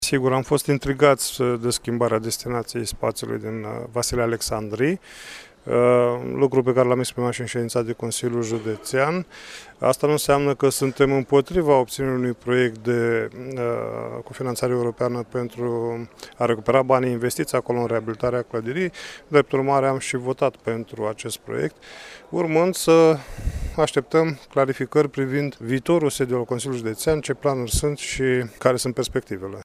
Proiectul a fost aprobat în cele din urmă, dar consilierul liberal Romeo Vatră s-a plâns că nu pot fi transformate în muzee toate clădirile renovate.